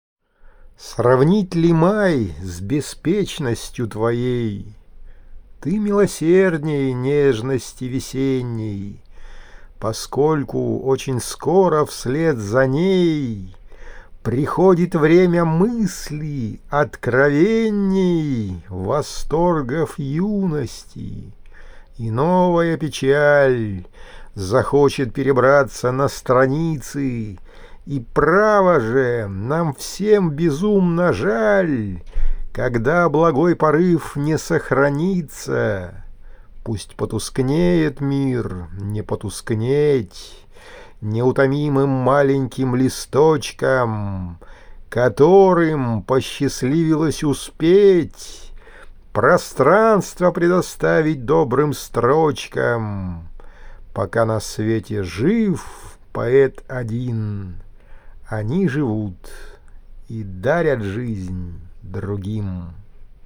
• Жанр: Декламация